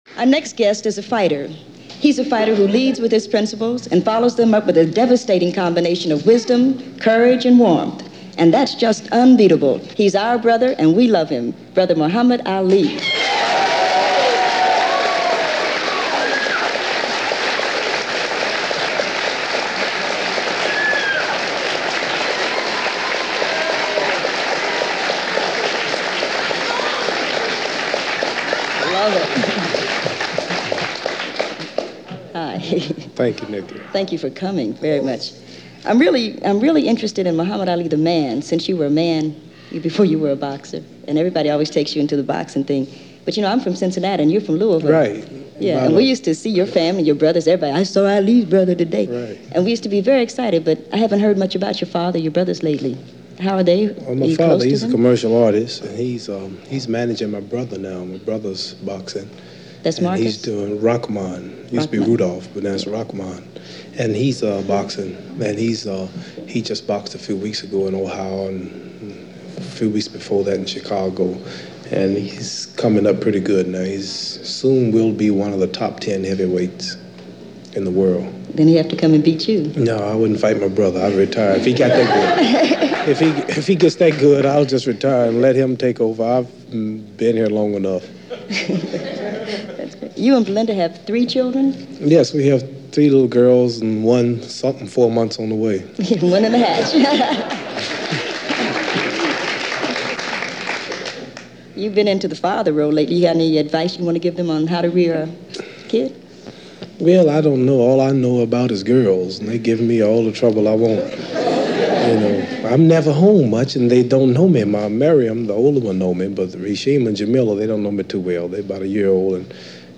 Muhammad Ali in conversation with poet Nikki Giovanni in 1972 - from the NET series Soul! - Past Daily Tribute Edition - RIP: Muhammad Ali.
So I ran across this interview, conducted by Poet and writer Nikki Giovanni for the NET (pre-PBS) program Soul! from January 5, 1972. And even though he does devote some time to his legendary moments, he also talks about his activism, his cautions to young Black men, growing up and dreaming of a career in the ring – talking about his kids and the people around him.